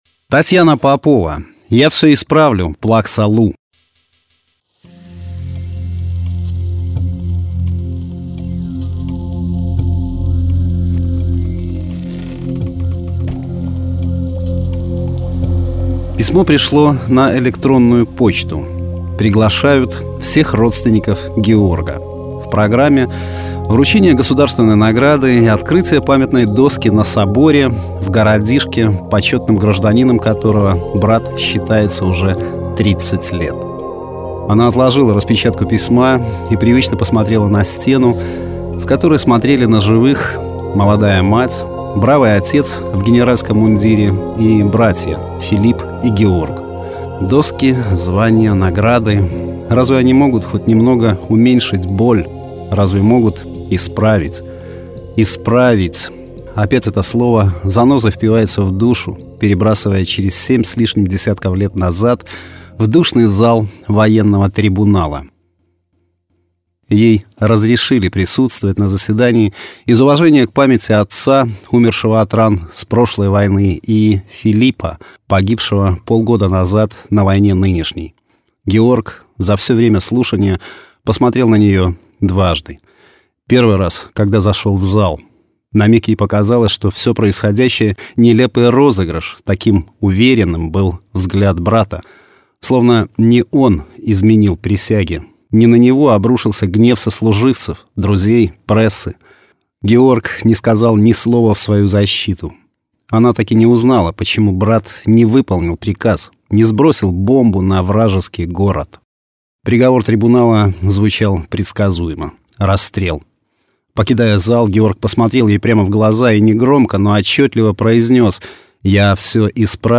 Аудиорассказ: Татьяна Попова. Я всё исправил, плакса Лу
Жанр: Современная короткая проза
Качество: mp3, 256 kbps, 44100 kHz, Stereo